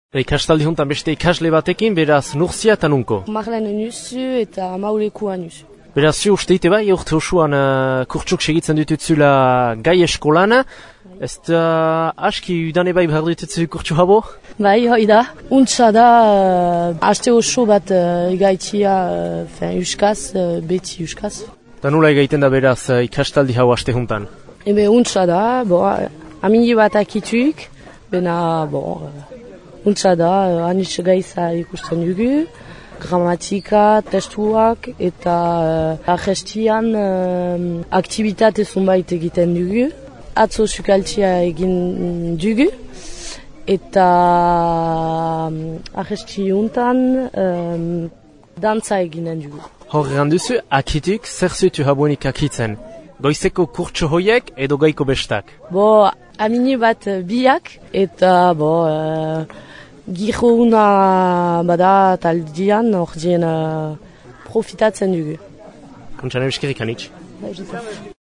Entzün ikastaldian den Mauletar bat :
ikastaldia2012mauletarbat.mp3